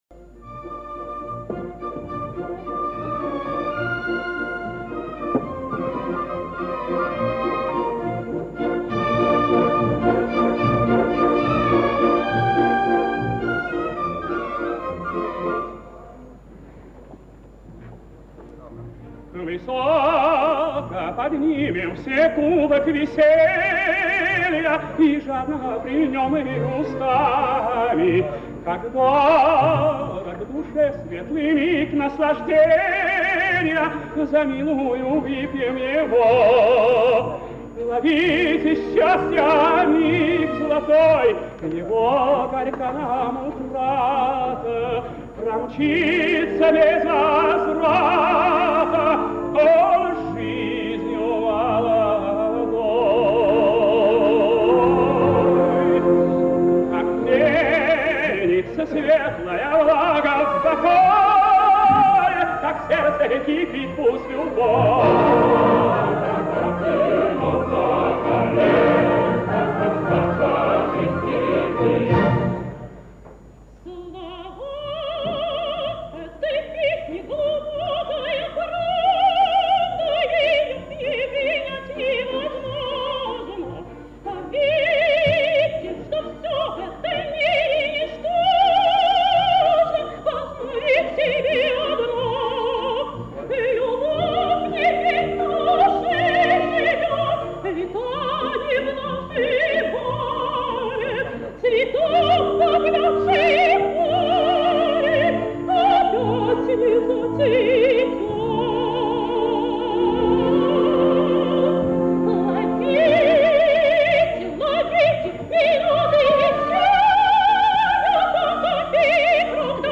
Обладала гибким выразительным голосом (лирическое сопрано), «ровным и наполненно звучащим во всех регистрах, на редкость серебристого тембра», великолепной филировкой ( С. 90), драматическим темпераментом.
Застольная. Хор и оркестр Большого театра. Дирижёр Б. Э. Хайкин. В партии Виолетты – М. П. Амиранашвили, в партии Альфреда – С. Я. Лемешев.